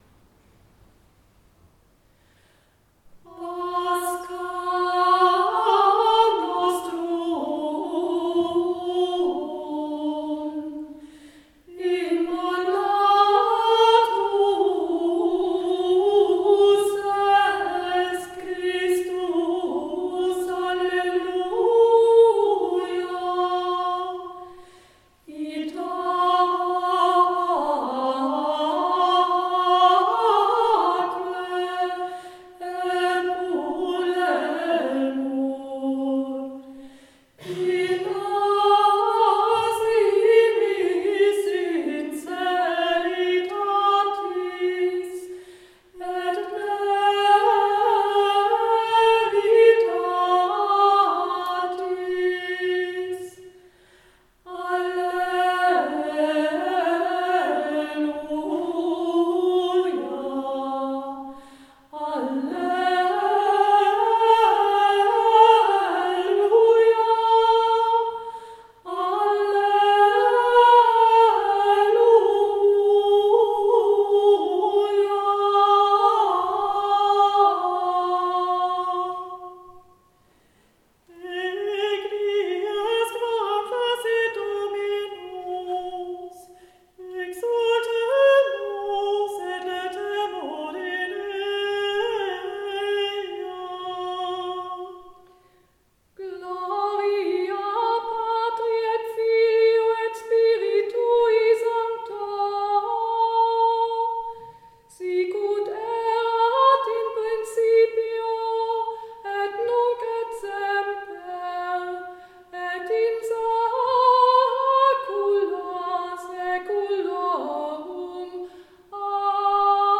Ostermesse des Mittelalters (10.-12.Jh.)
Noch während der "Fractio panis" erklang das AGNUS DEI (Lamm Gottes, das die Sünde der Welt trägt) - römischer Gesang seit dem 7.Jh. Während der Austeilung der Eucharistie wird die COMMUNIO mit ihrem dreifachen Alleluia gesungen: Pascha nostrum (Als unser Opferlamm geopfert wurde - Christus - Alleluia).